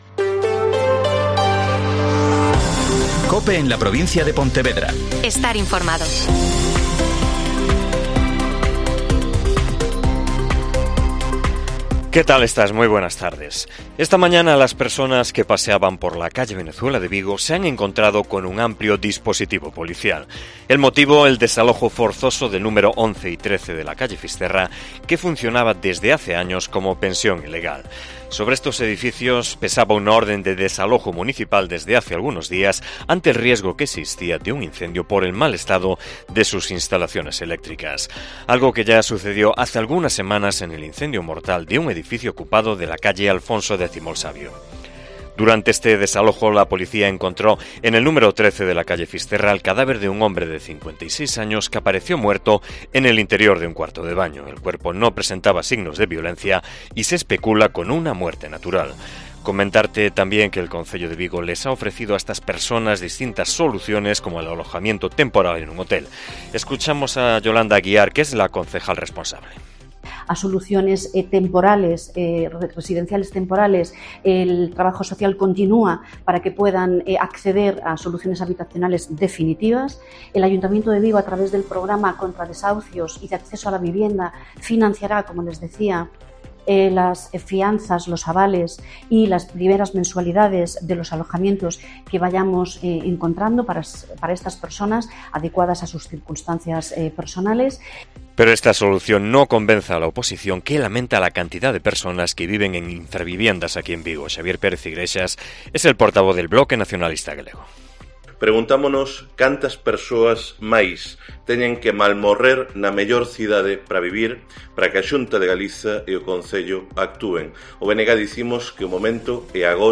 AUDIO: Informativo provincial